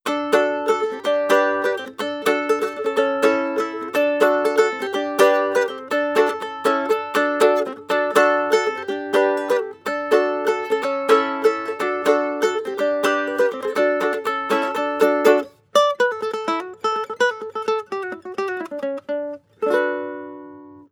• guitarrico country sequence.wav
guitarrico_country_sequence_ksG.wav